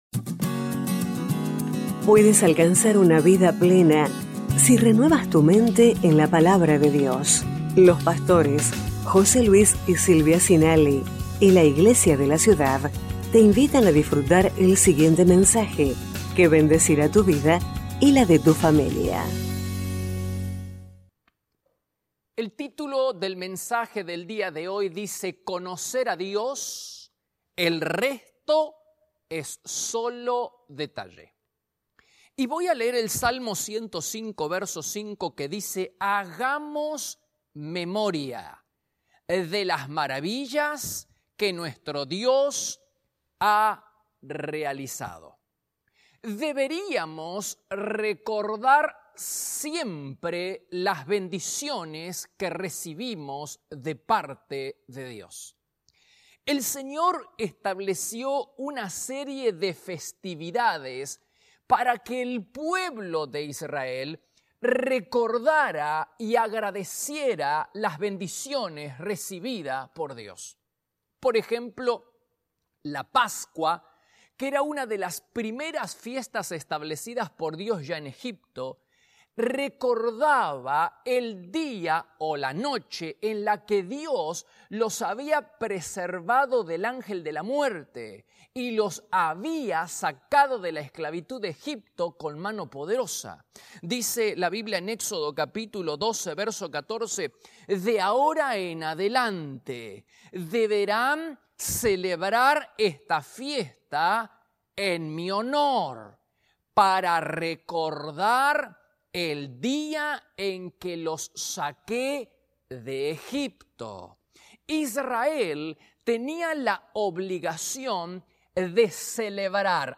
Iglesia de la Ciudad - Mensajes / Conocer a Dios.